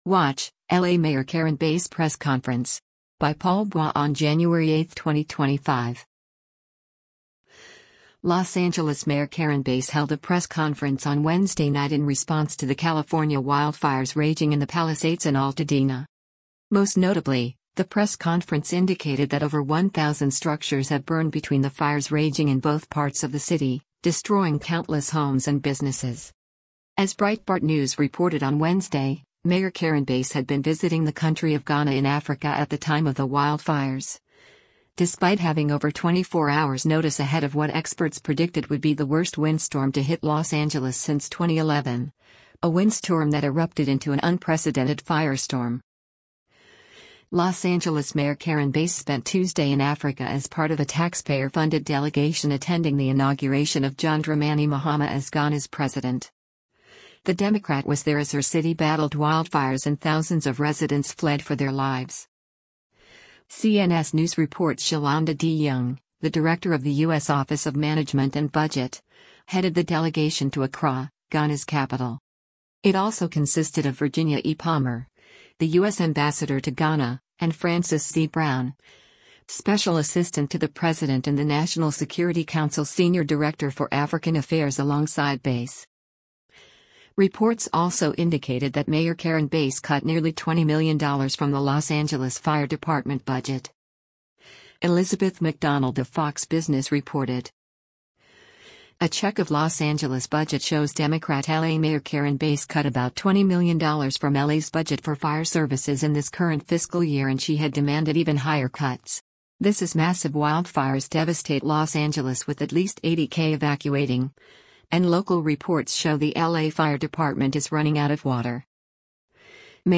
Los Angeles Mayor Karen Bass held a press conference on Wednesday night in response to the California wildfires raging in the Palisades and Altadena.